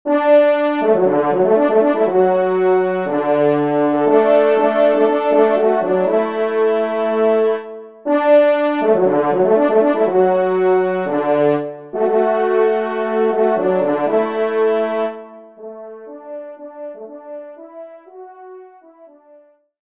Genre :  Divertissement pour quatre Trompes ou Cors
Pupitre 3° Trompe / Cor